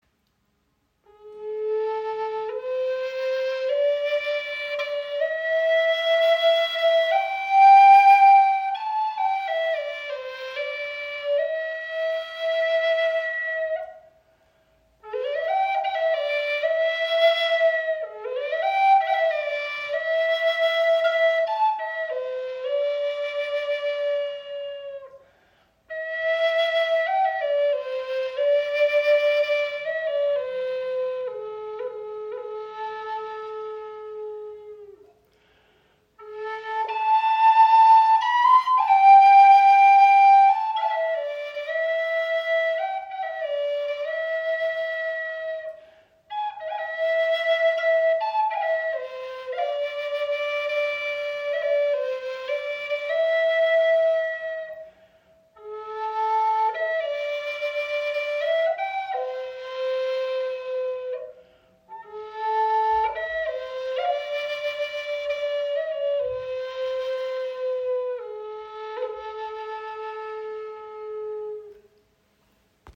Ihr warmer, kraftvoller Ton begleitet Dich sanft auf Deiner Reise ins Innere.